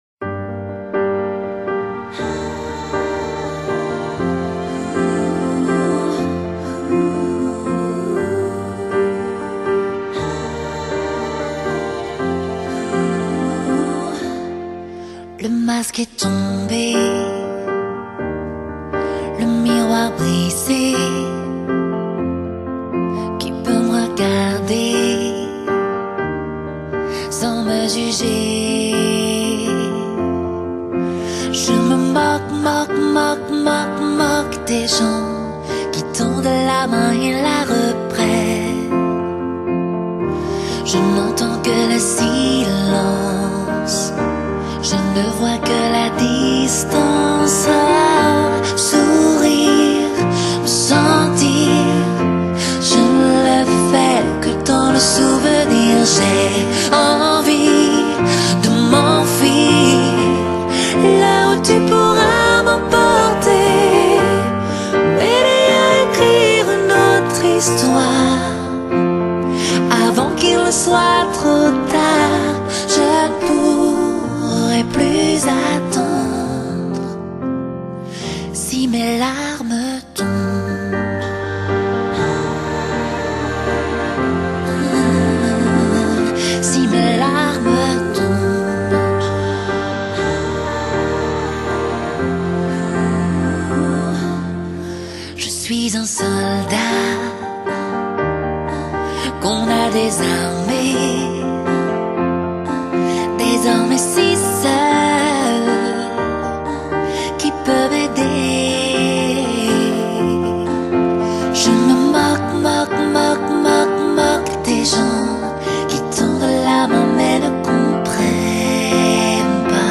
Genre: Pop, French, Chanson